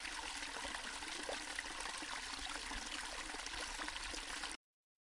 涓涓细流的水溪
描述：一点点快速移动的流，用h1接近mic'ed捕获
Tag: 潺潺 小溪 放松